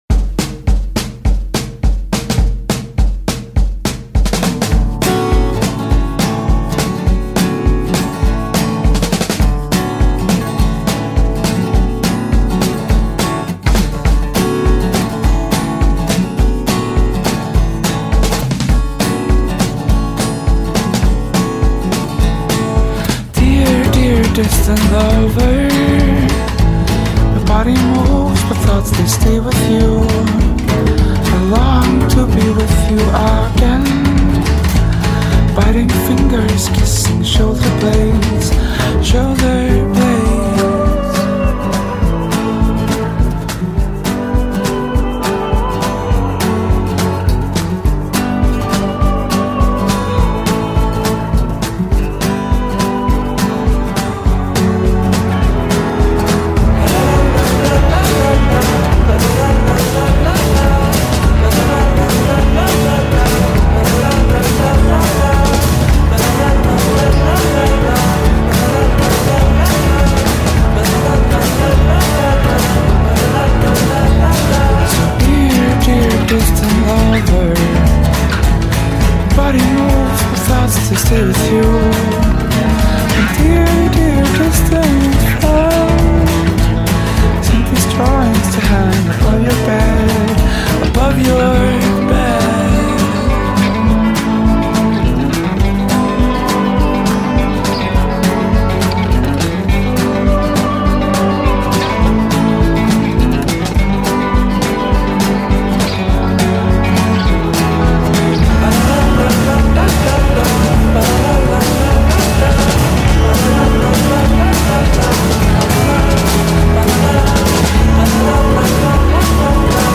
Vocals, guitars
Violin, viola, piano
Drums